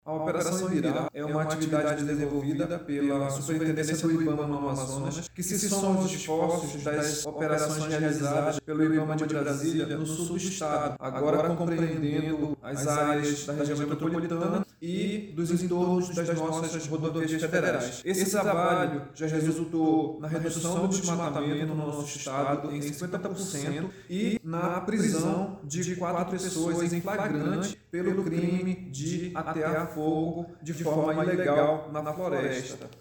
Além da fiscalização, a operação também realiza atividades educativas, orientando os moradores das áreas próximas sobre práticas agrícolas sustentáveis, evitando o uso do fogo e incentivando a denúncia de irregularidades, como explica o superintendente do IBAMA-AM, Joel Araújo.
SONORA-1-Joel-Araujo.mp3